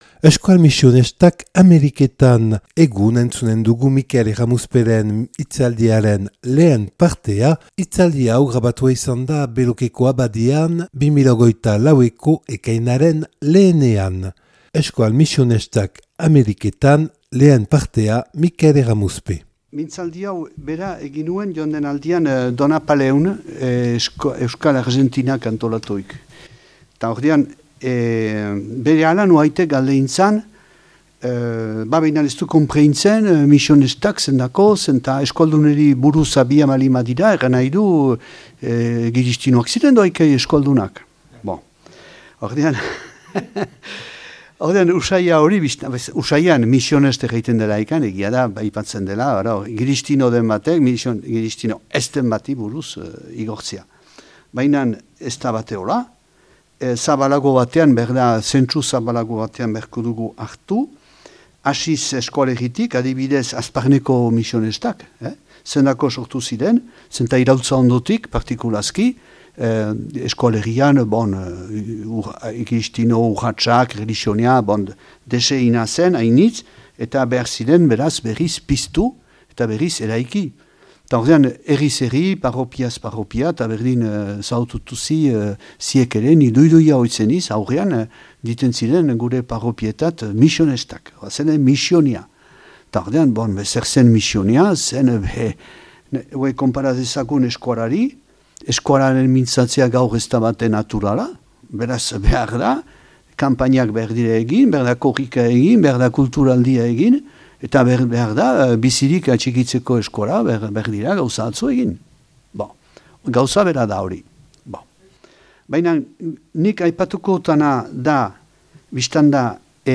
(Belokeko Abadian grabatua 2024. ekainaren 1ean)